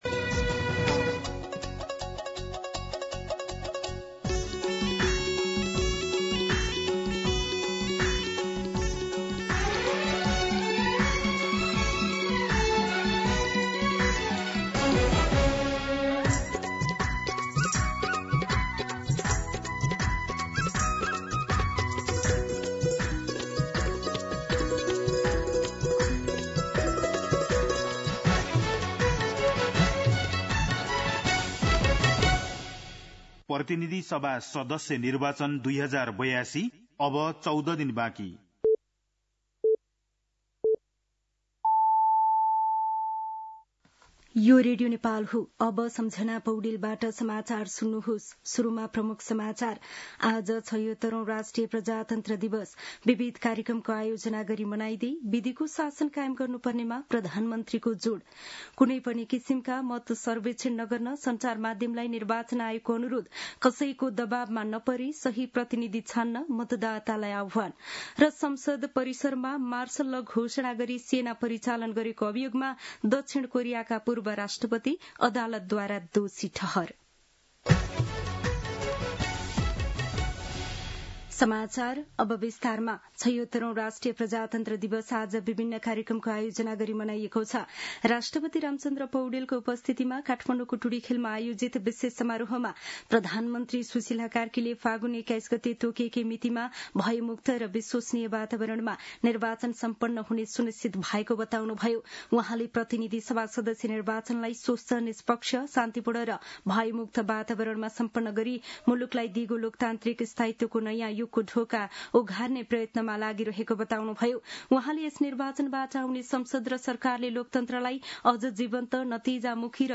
दिउँसो ३ बजेको नेपाली समाचार : ७ फागुन , २०८२